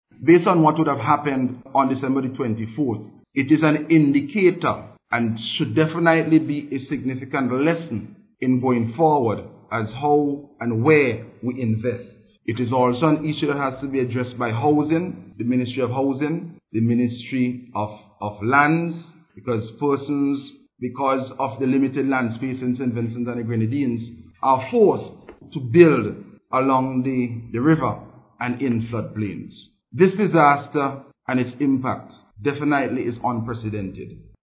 Speaking at a news conference on Monday, Minister Ceasar said the devastation caused by the December 24th floods highlighted the need for improved warning systems.